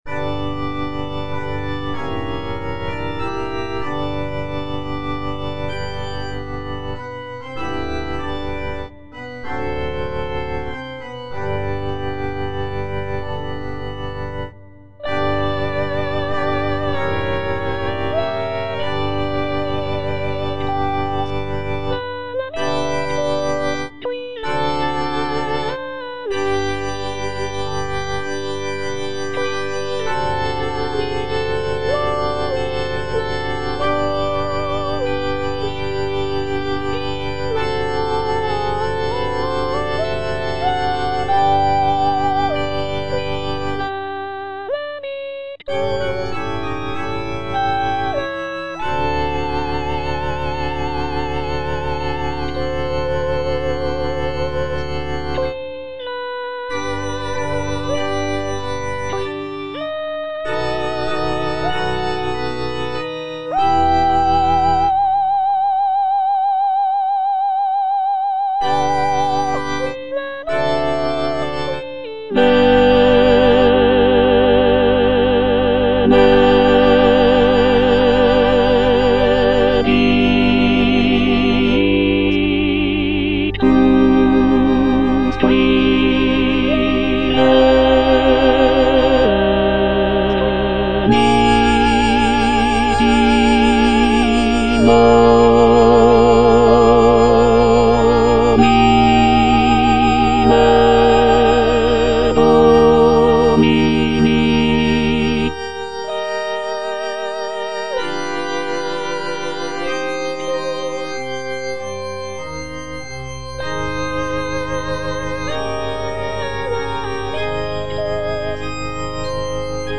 C.M. VON WEBER - MISSA SANCTA NO.1 Benedictus - Tenor (Emphasised voice and other voices) Ads stop: auto-stop